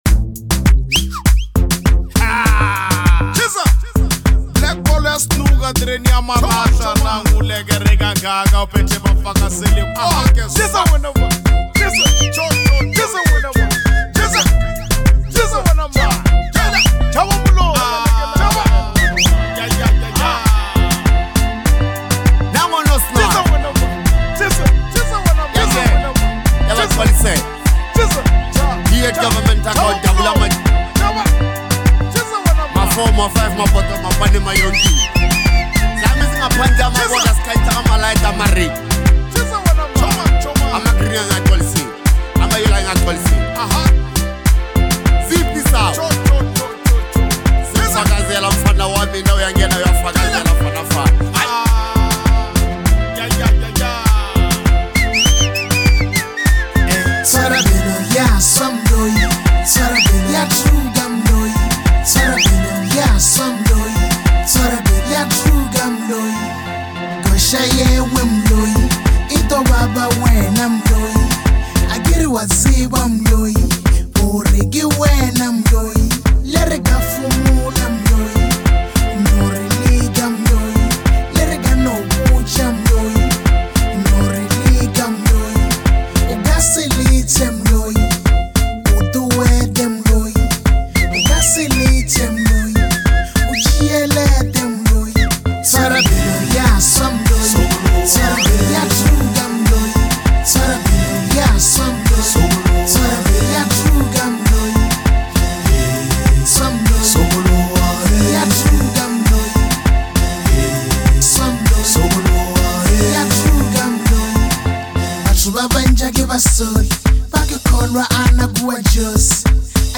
Bolo HouseBolobedu House